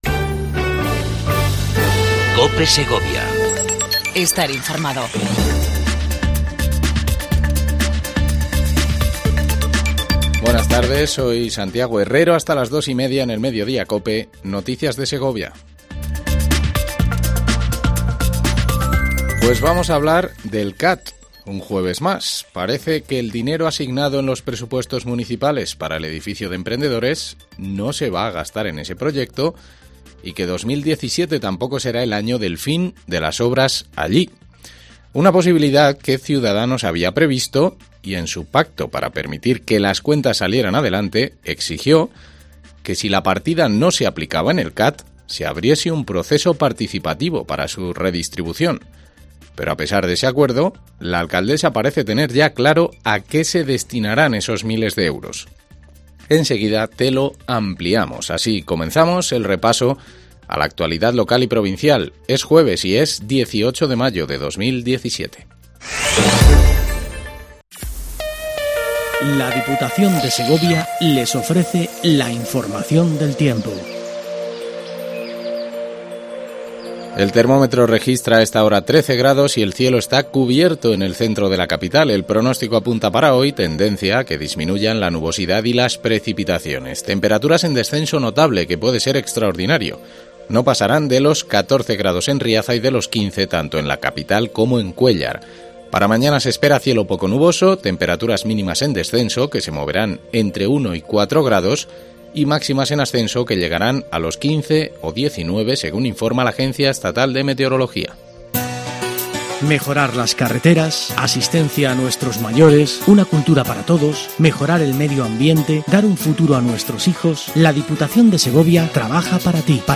INFORMATIVO MEDIODIA COPE EN SEGOVIA 18 05 17